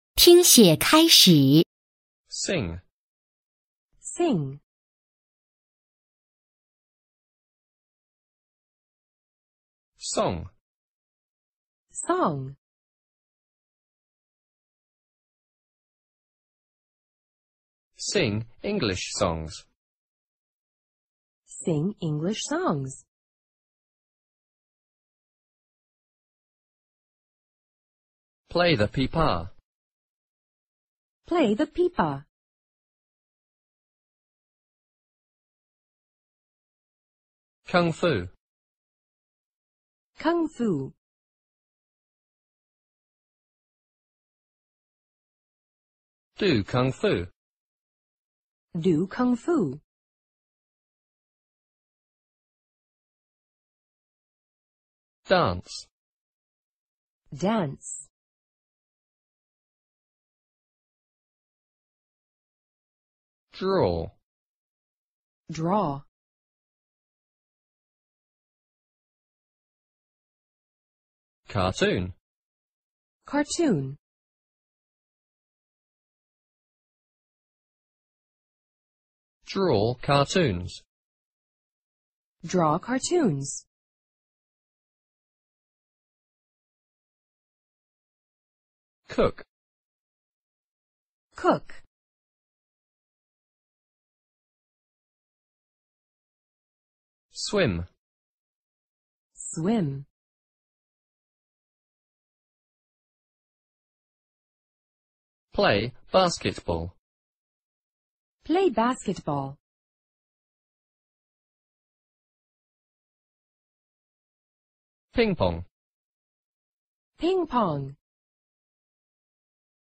Unit 4 单词【听写】（人教版PEP五年级上册）